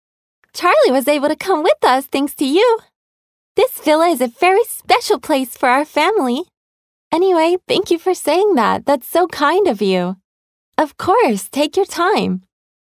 Voice actor sample
밝음/희망